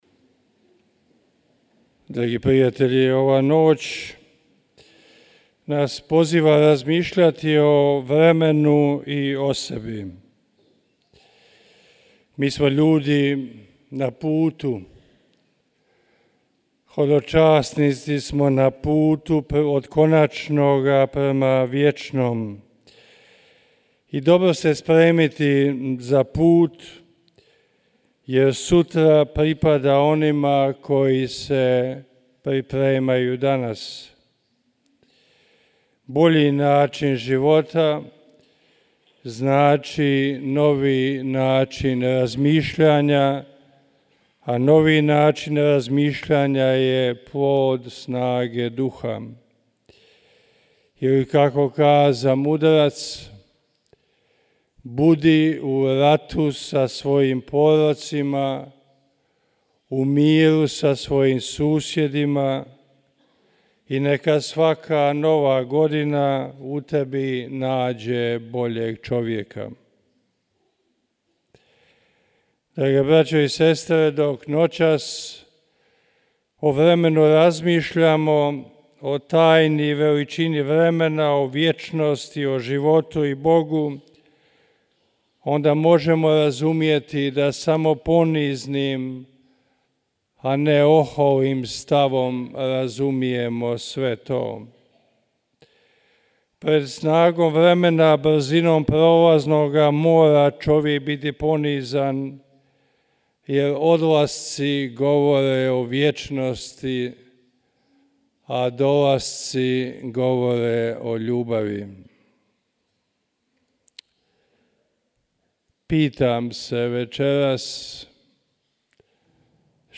Uz međugorske župljane, daleko od buke svijeta Novu godinu slaveći svetu misu u Međugorju dočekale su i tisuće hodočasnika iz: Austrije, Njemačke, Slovenije, Italije, Španjolske, Češke, Poljske, Slovačke, Rumunjske, Ukrajine, Mađarske, SAD, Koreje, Hrvatske, Bosne i Hercegovine…